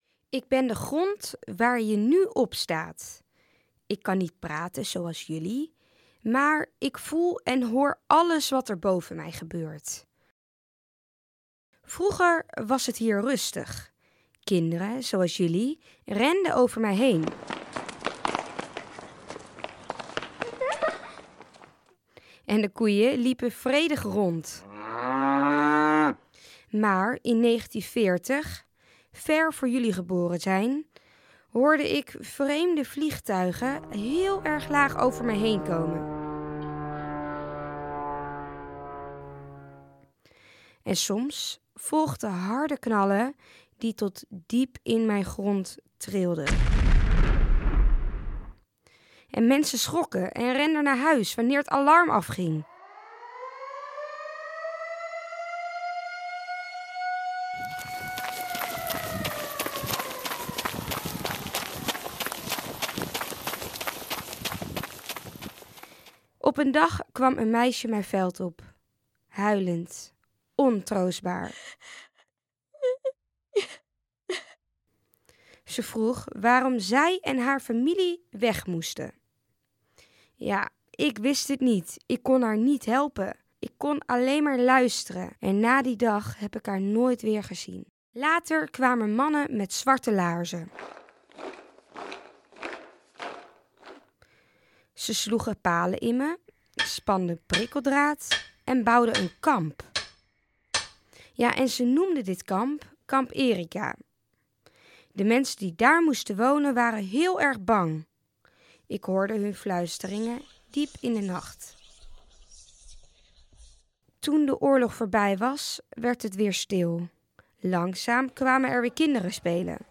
In dit narratieve audioverhaal wordt die geschiedenis verteld vanuit een bijzonder perspectief: de grond zelf.